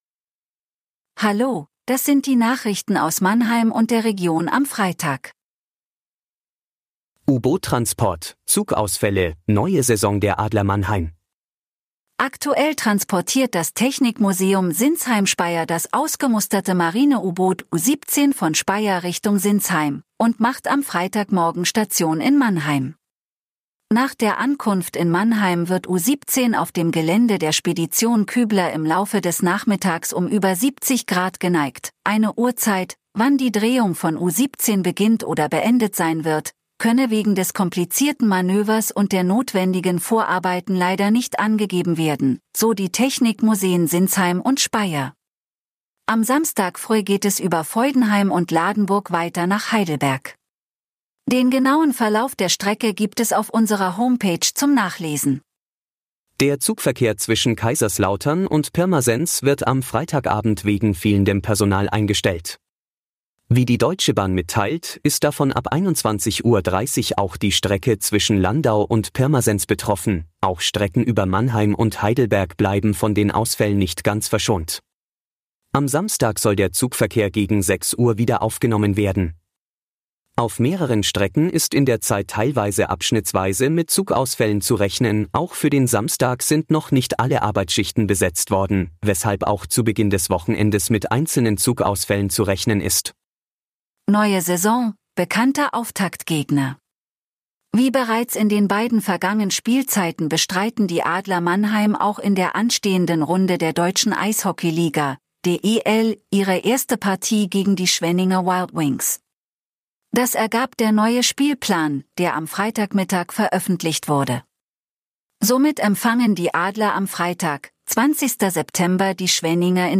Der Nachrichten-Podcast des MANNHEIMER MORGEN